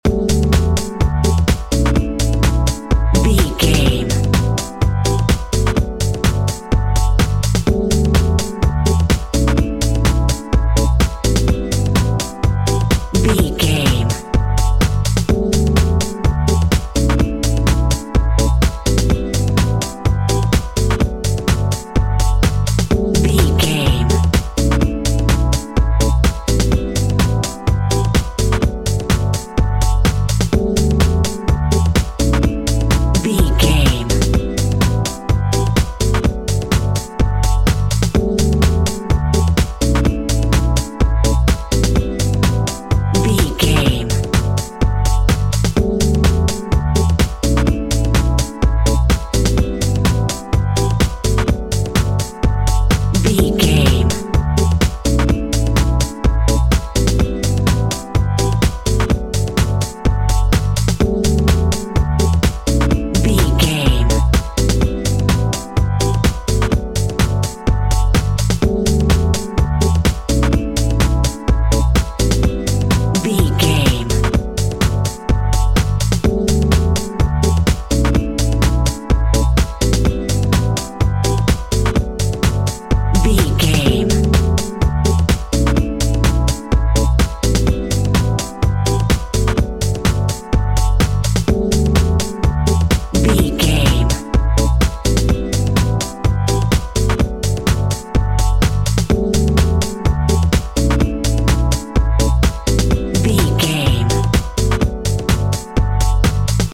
Aeolian/Minor
uplifting
energetic
bouncy
funky
bass guitar
synthesiser
electric piano
drum machine
Drum and bass
break beat
electronic